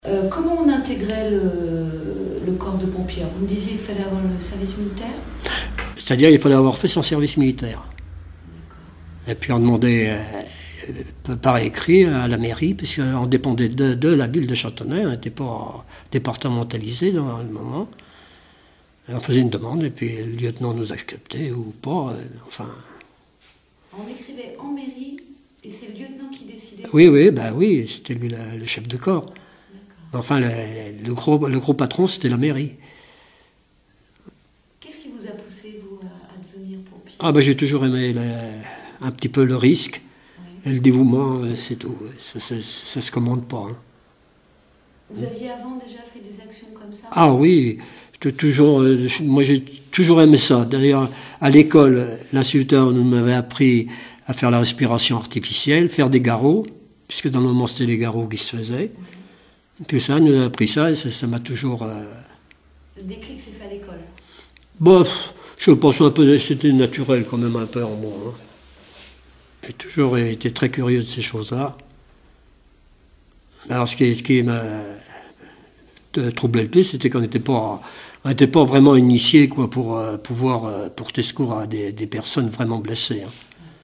Témoignages d'un ancien sapeur-pompier